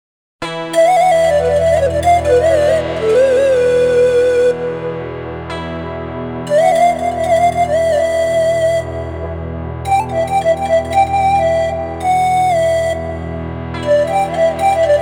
Cultural